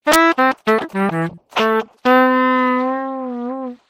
描述：A prosody transformation from a Catalan speech sentence performed using smstools and HPS transformations. Timestretching.
标签： transformations speech hps
声道立体声